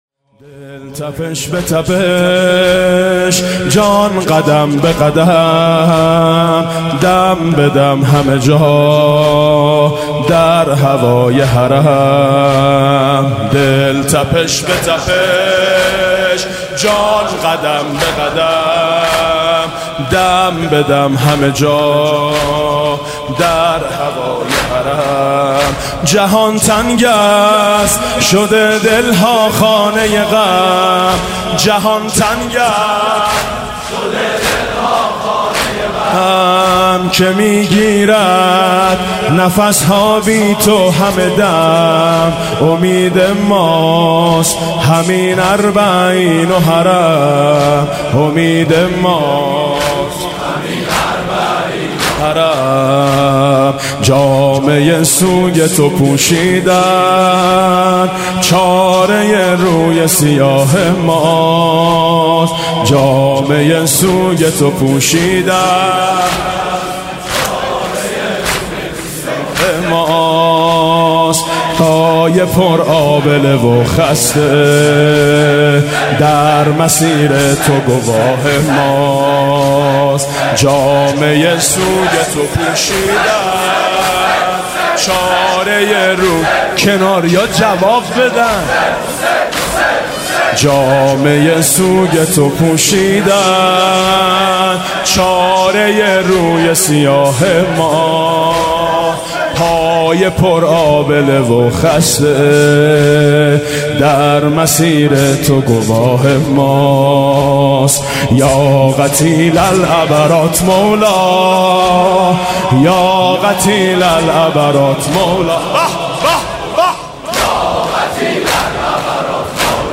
زمینه - امید ماست همین اربعین و حرم